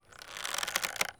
Bow Charge.wav